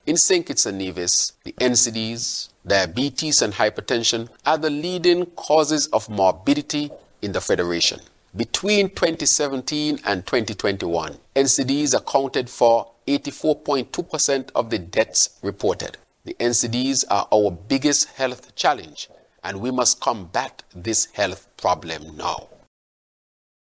In an address to mark the occasion, Prime Minister and Federal Minister of Health, Dr. Terrance Drew, said NCDs must be dealt with.